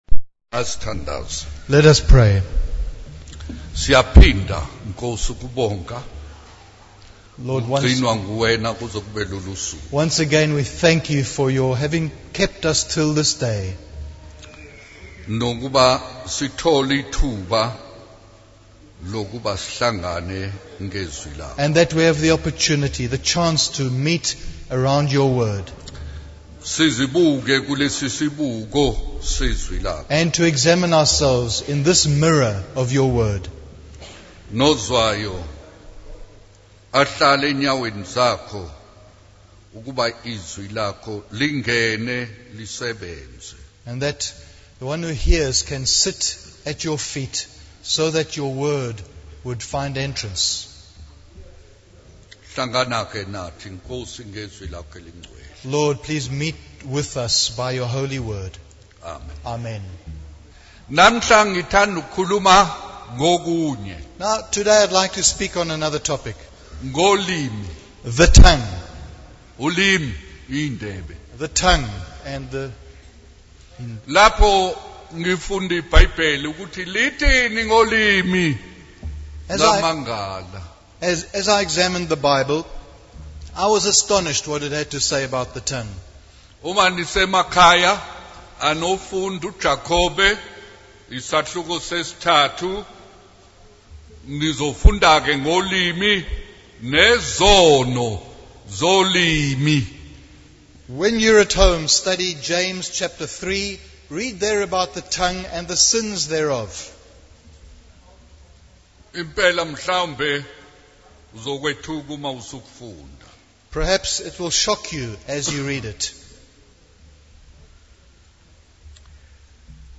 In this sermon, the preacher emphasizes the importance of being cautious about the content we consume, particularly videos. He warns against allowing negative influences into our lives and encourages parents to monitor what their children watch.